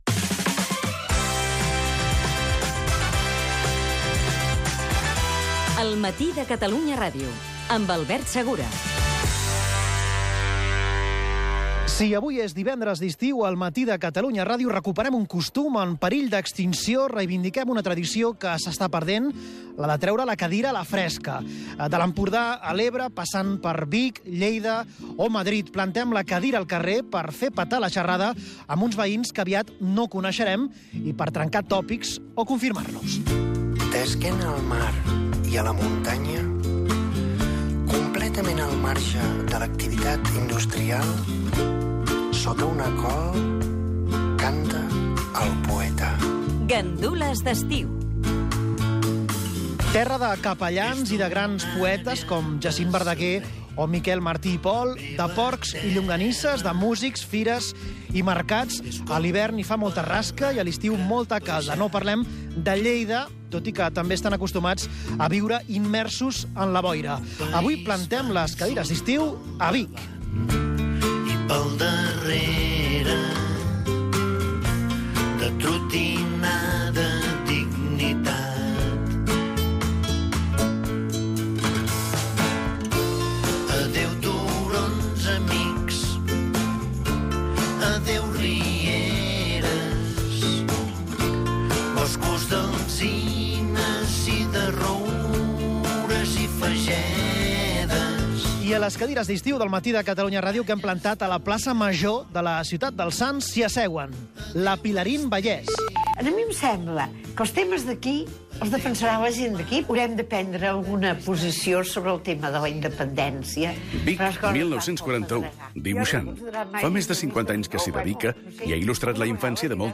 Info-entreteniment
FM